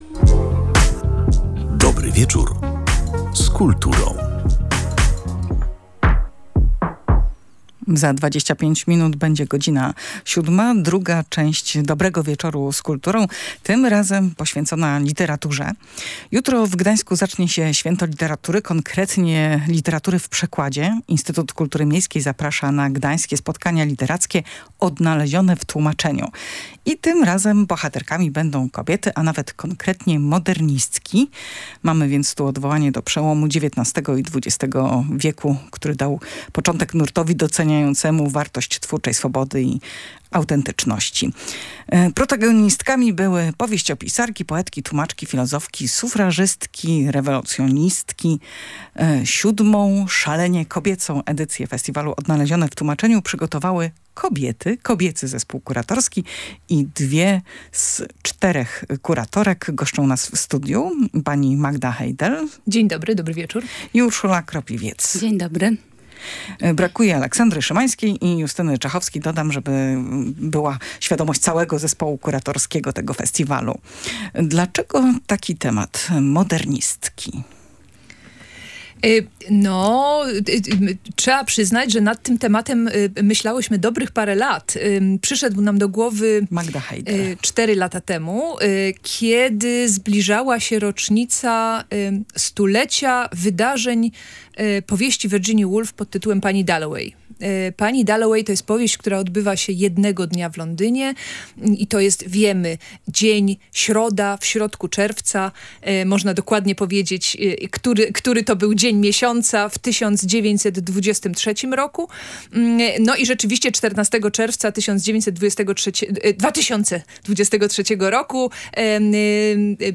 O tym opowiadały w audycji na żywo dwie z czterech kuratorek festiwalu